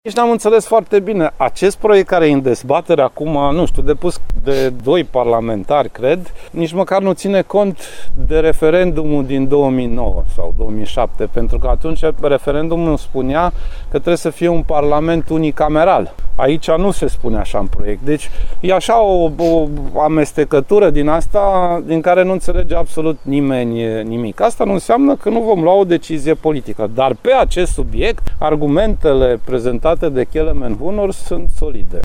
Președintele interimar al PSD a declarat, la Timișoara, că o decizie în acest sens va fi luată în Consiliul Național Politic.